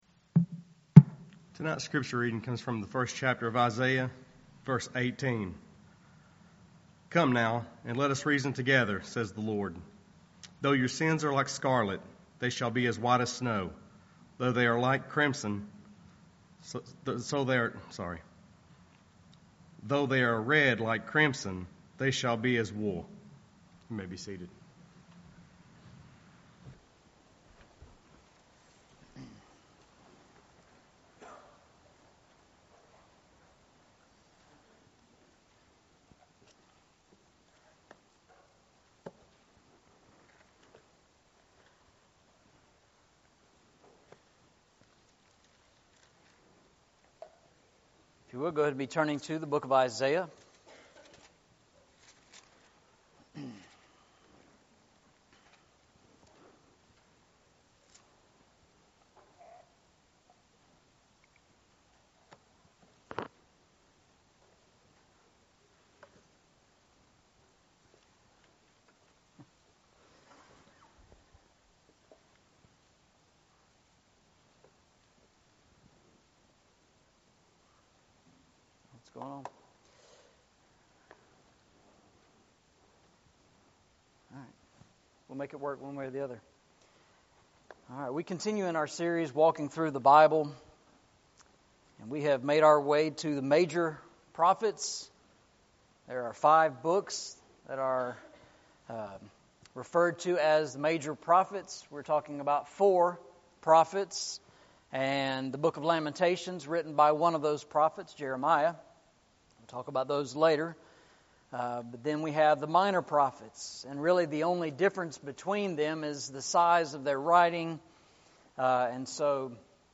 Eastside Sermons
Service Type: Sunday Evening